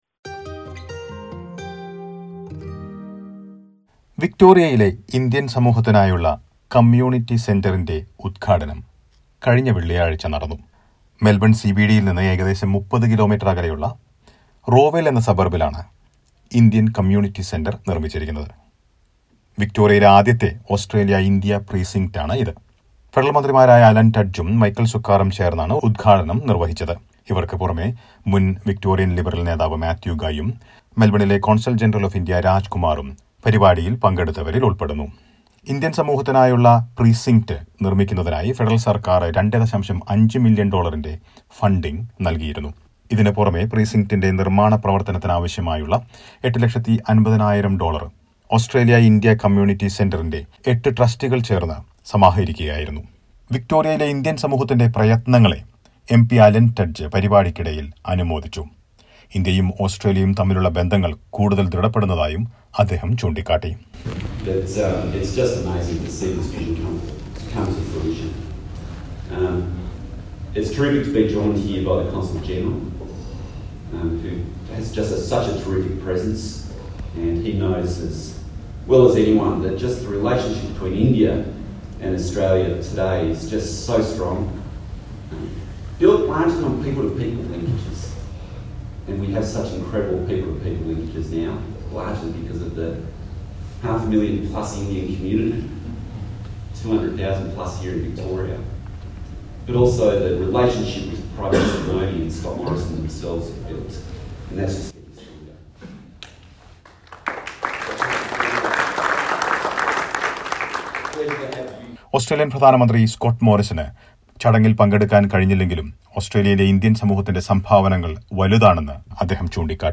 The Victorian Indian community gets its own community center. Listen to a report.